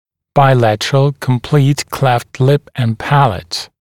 [baɪ’lætərəl kəm’pliːt kleft lɪp ən ‘pælət][бай’лэтэрэл кэм’пли:т клэфт лип эн ‘пэлэт]двусторонняя полная расщелина губы и нёба